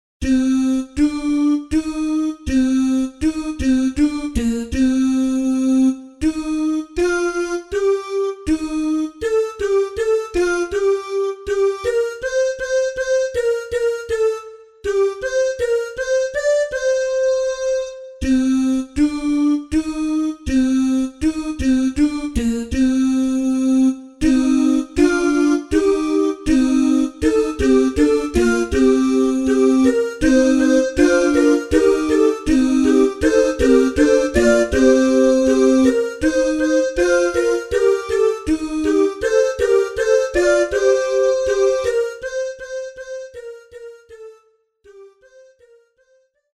CANONS